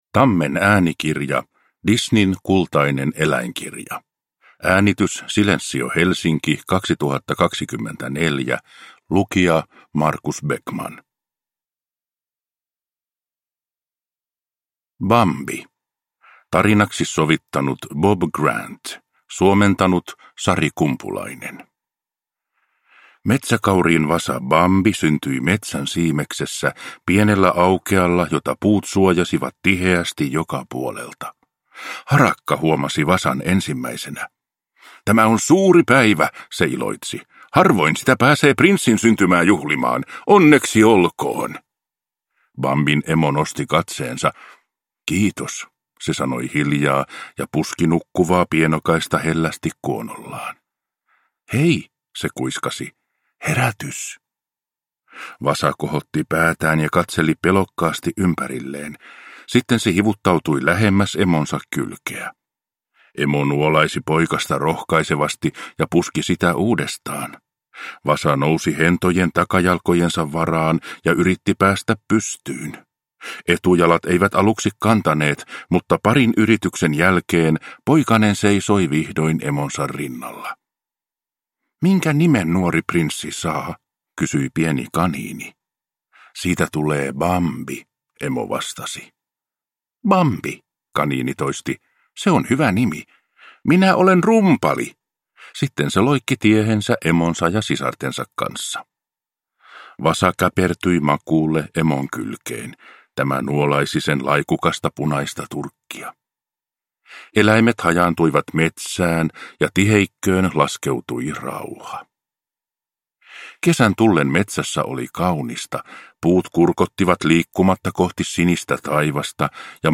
Disneyn kultainen eläinkirja – Ljudbok